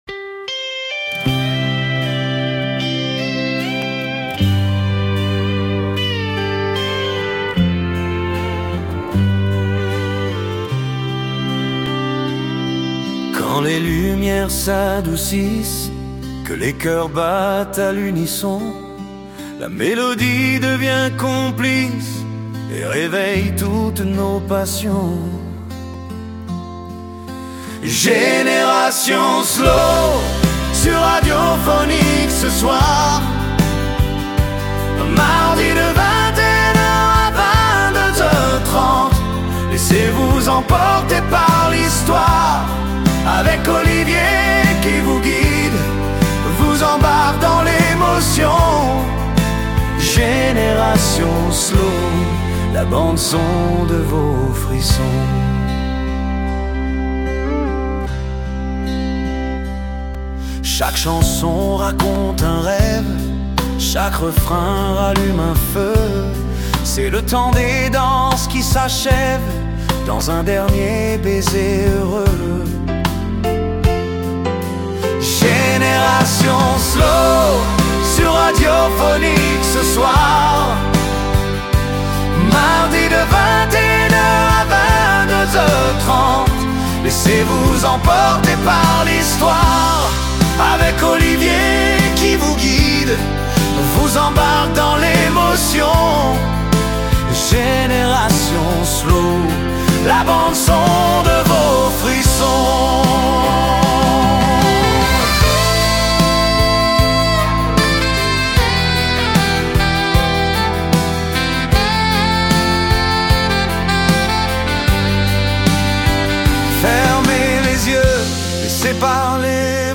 Dans ce nouveau podcast, retrouvez une sélection de slows choisis avec soin… Des chansons qui racontent l’amour, le vrai.